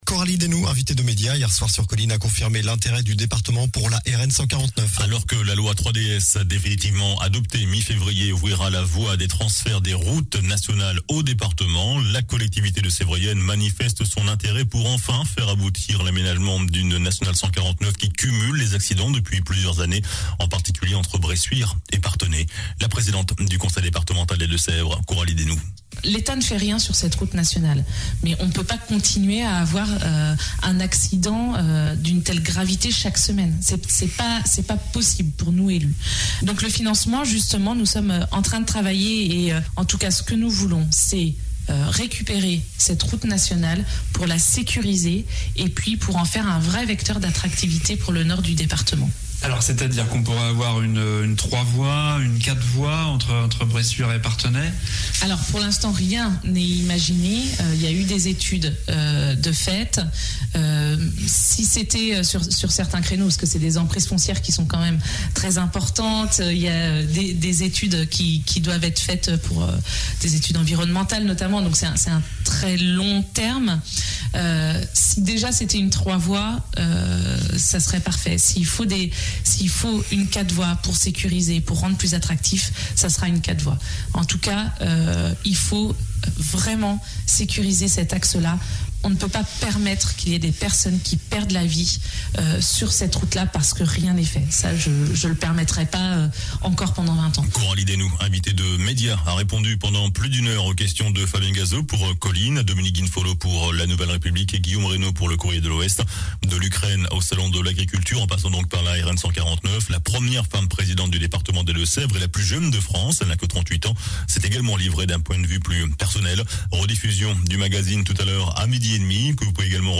JOURNAL DU SAMEDI 26 FEVRIER